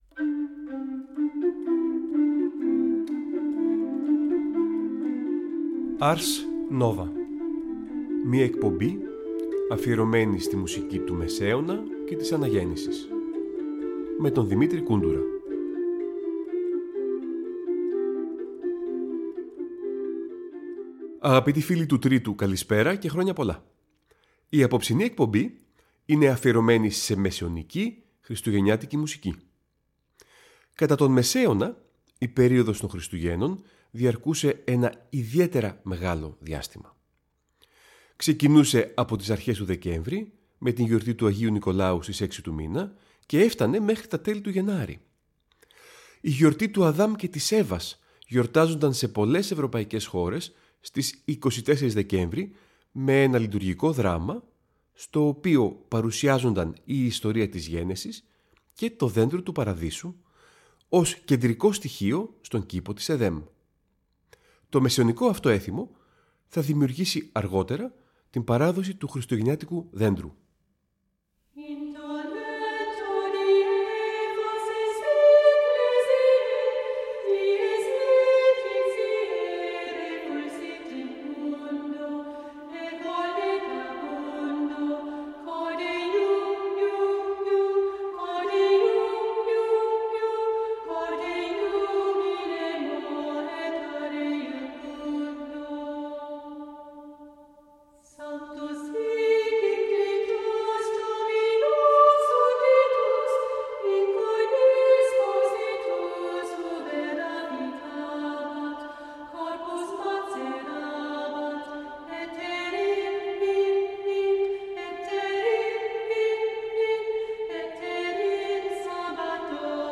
Νέα ωριαία μουσική εκπομπή του Τρίτου Προγράμματος που θα μεταδίδεται κάθε Τρίτη στις 19:00.